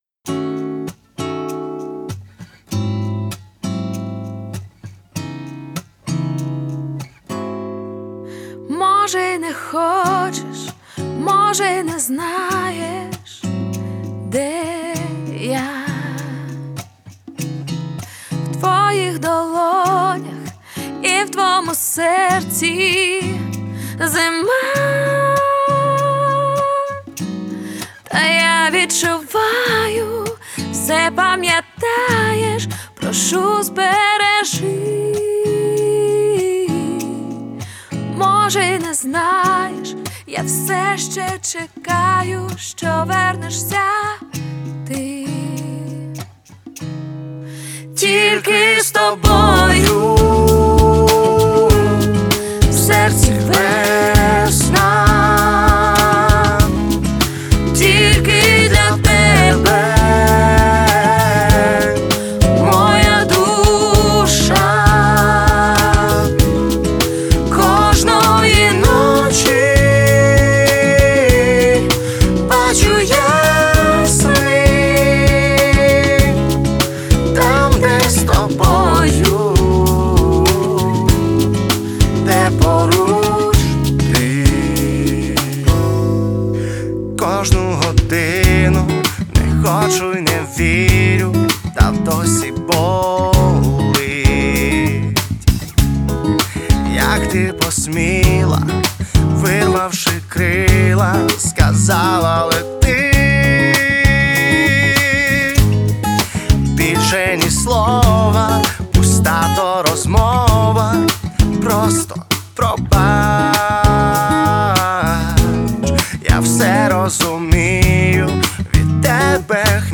Тенор